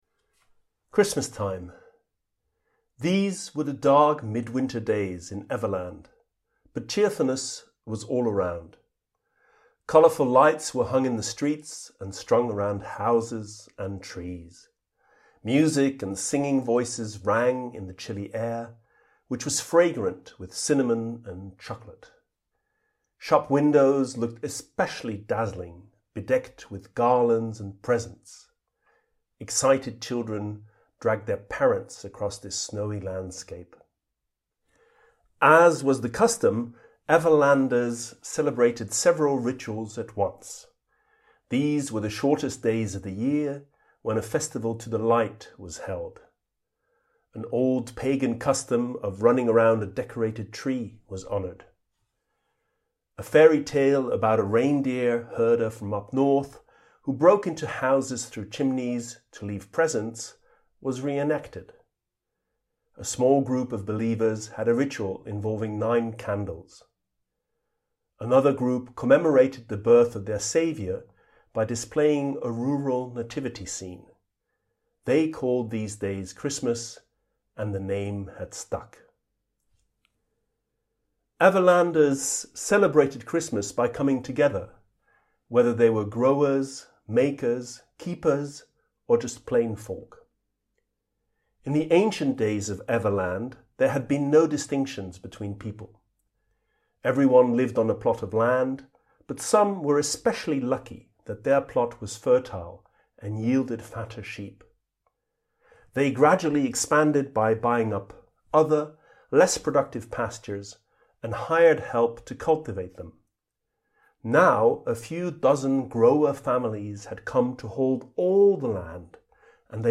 Each episode, recorded on a walk, explores a concept in economics, ecology, psychology or philosophy.